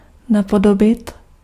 Ääntäminen
US : IPA : /ˈɪmɪteɪt/